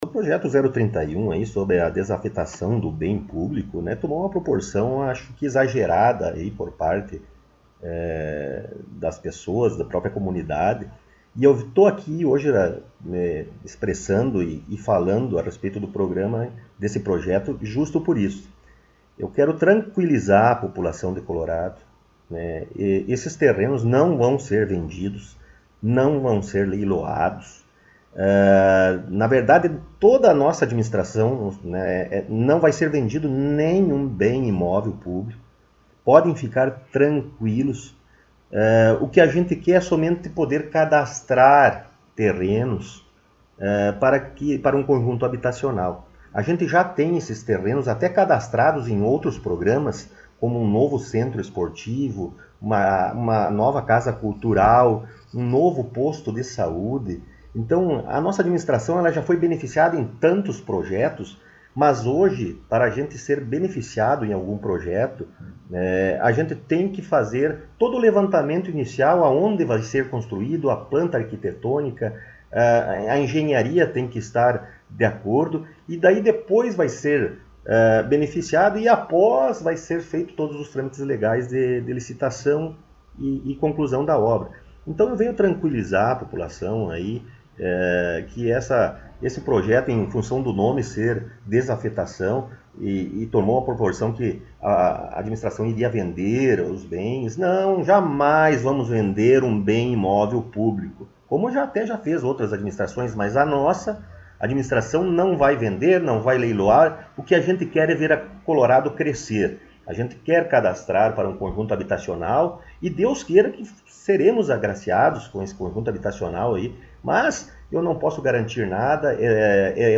Estivemos mais uma vez em entrevista com o prefeito Rodrigo Sartori em seu gabinete.